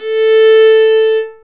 Index of /90_sSampleCDs/Roland L-CD702/VOL-1/STR_Viola Solo/STR_Vla3 _ marc
STR VIOLA 0B.wav